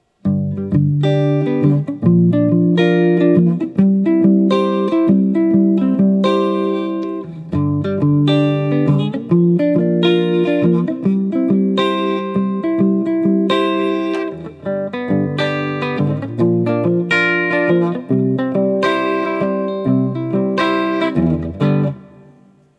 1984 X-500 Chords
Next, I set the LS-10 in front of my Axe-FX with the aforementioned Jazz patch.
With the guitars plugged in using equal amp settings, you can hear how the 1984 X-500 without the sound post is much boomier when the neck pickup is involved.